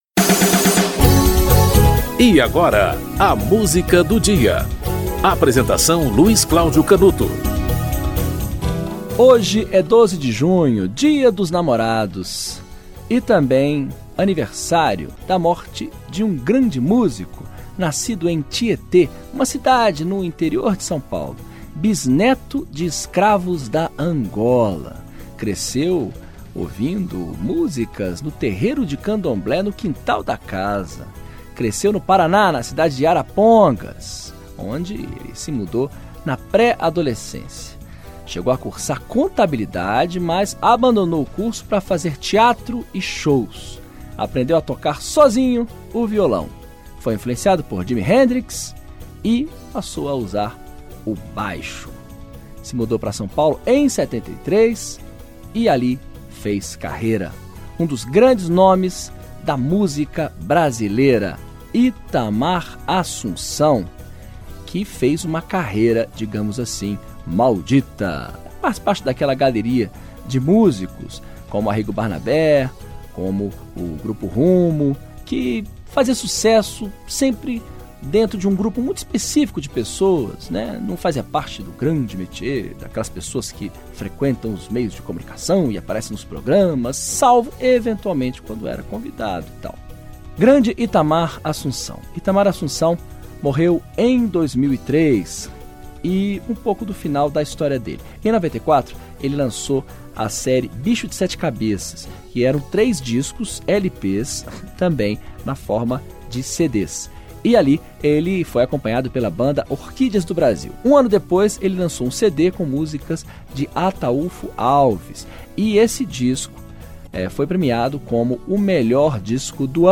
Produção e apresentação